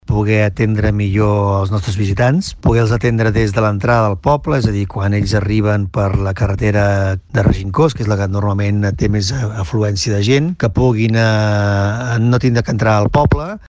L’Ajuntament de Begur ha instal·lat un nou punt d’informació a l’aparcament de la Vinya, just a l’entrada del poble d’on provenen més visitants, segons ha explicat a Ràdio Capital, el Regidor de Promoció Econòmica, Turisme i Comunicació de Begur, Eugeni Pibernat.